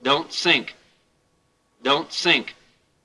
IDG-A32X/Sounds/GPWS/dont-sink.wav at f3eed539ecbef13bb89f6f3744b5b0366a4e47b7
dont-sink.wav